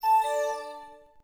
Mail.wav